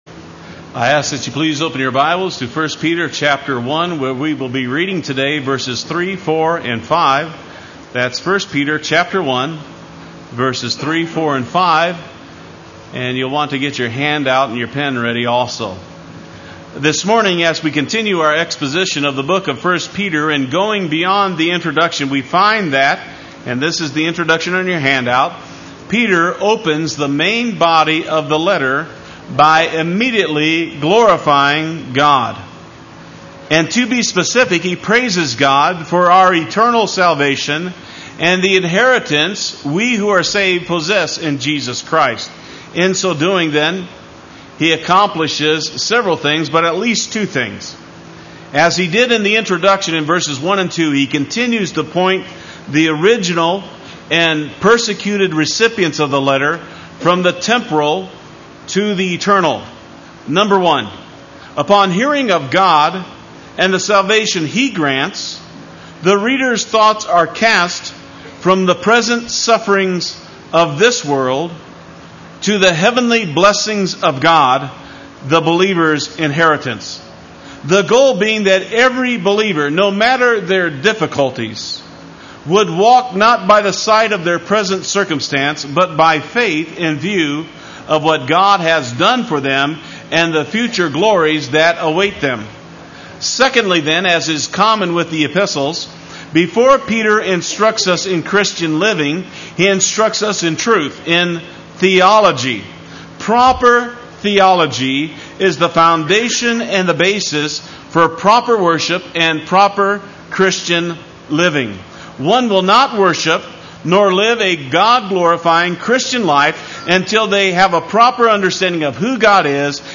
Play Sermon Get HCF Teaching Automatically.
Blessed be the God and Father Sunday Worship